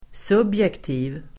Ladda ner uttalet
subjektiv adjektiv, subjective Uttal: [s'ub:jekti:v (el. -'i:v)] Böjningar: subjektivt, subjektiva Synonymer: partisk, ämne Definition: som baseras (enbart) på ens egna värderingar Exempel: subjektiva omdömen (subjective judgements)